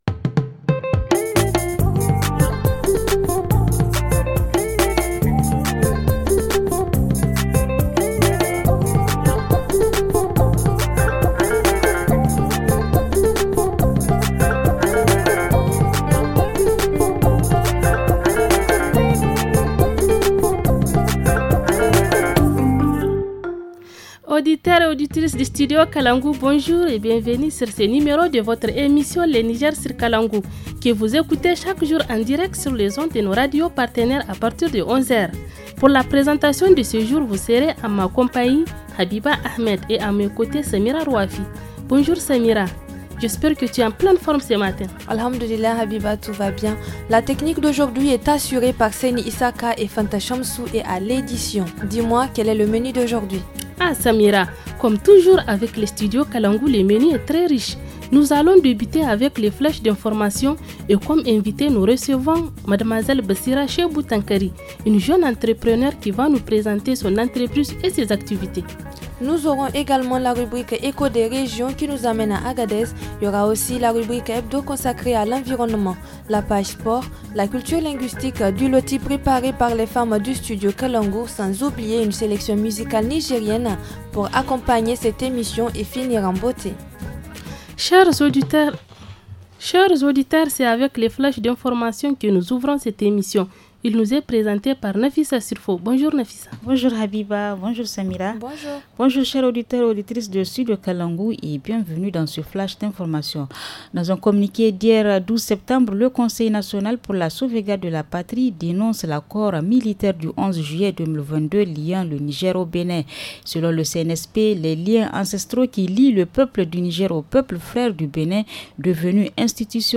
-Rubrique Hebdomadaire : Production de semences à Tanout ;